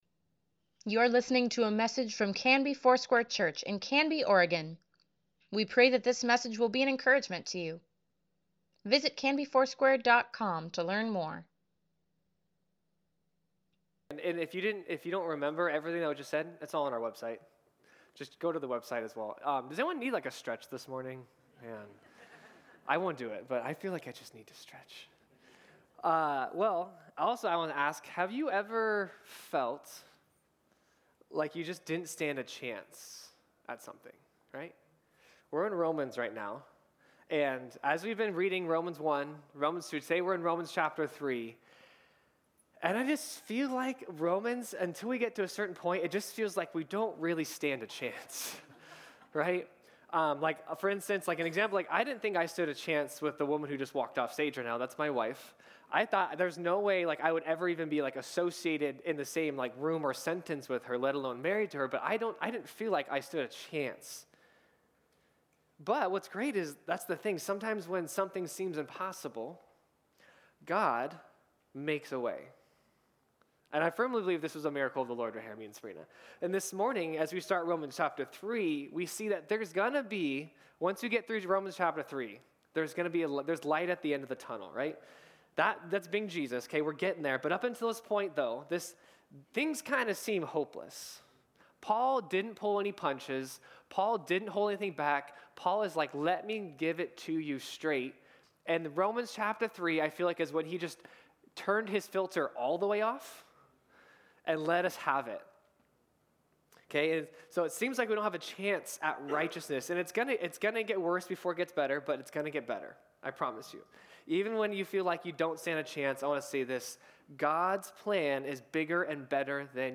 Sunday Sermon | March 11, 2024